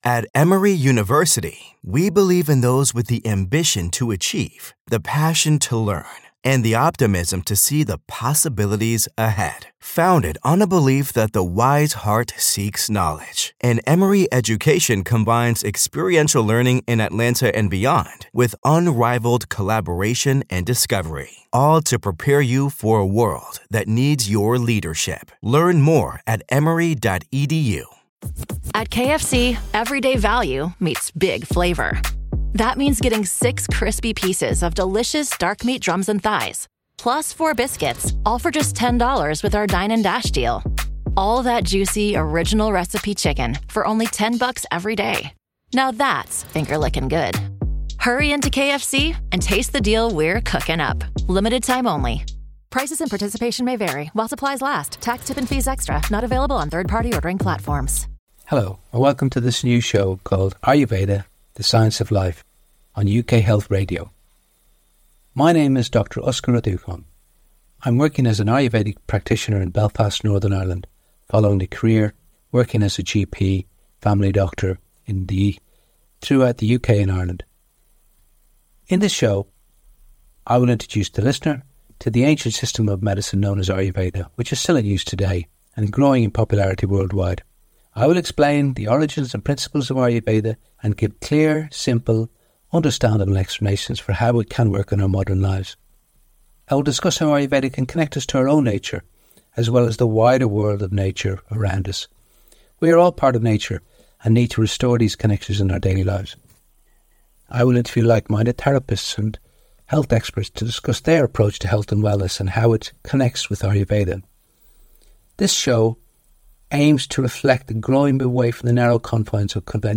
I'll interview therapists and health experts who share a holistic view of wellness. Ayurveda uses food and herbal remedies to address the root causes of illness, emphasising the link between physical symptoms and unresolved emotional issues.